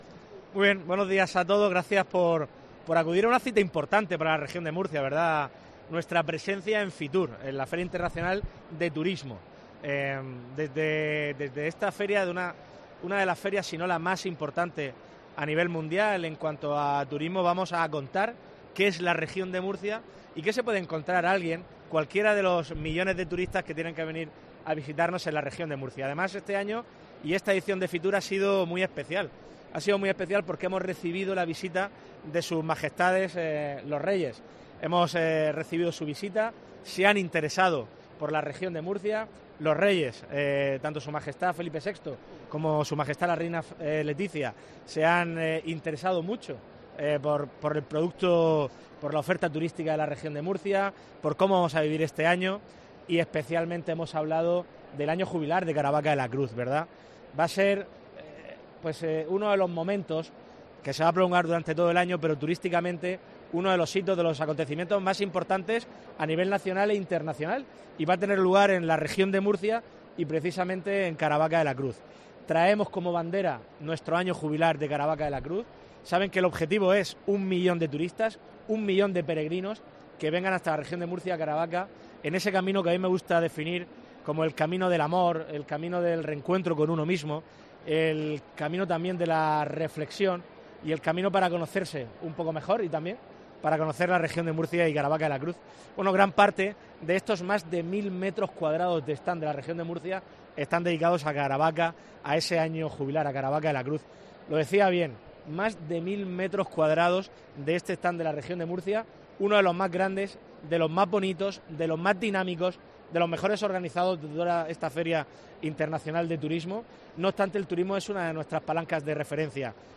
Fernando López Miras, presidente de la Región de Murcia en FITUR